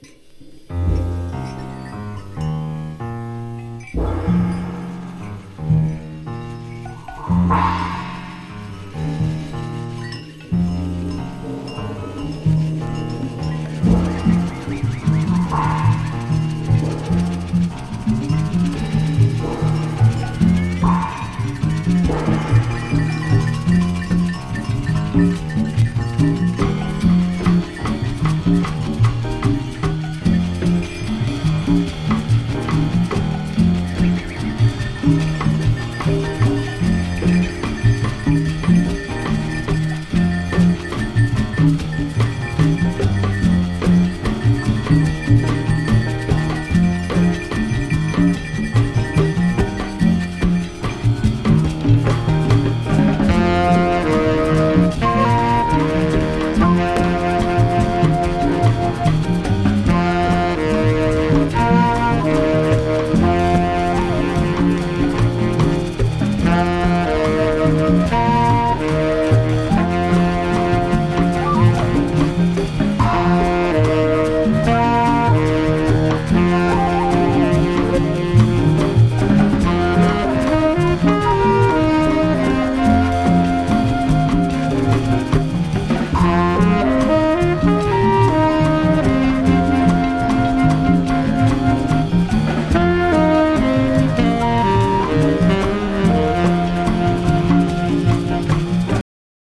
ジャケットからも漂う怪しさはスピリチュアルジャズと言われるジャンルに分類される内容です。